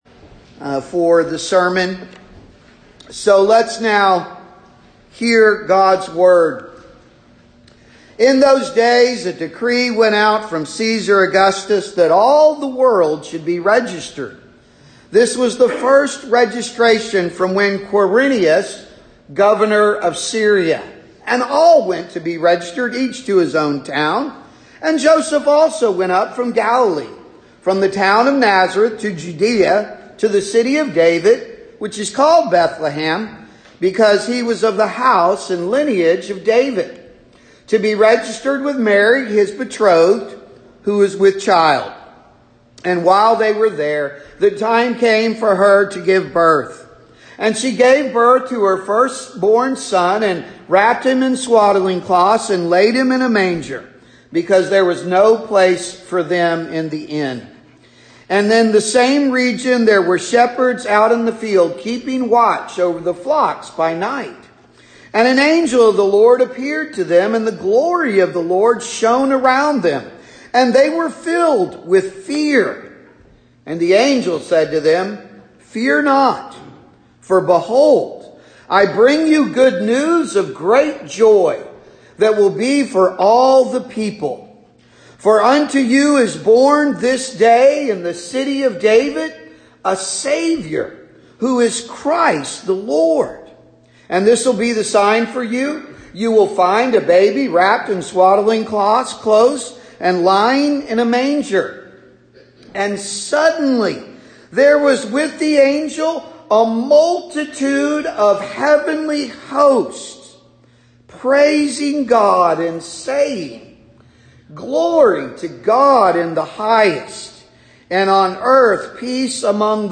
December Audio Sermons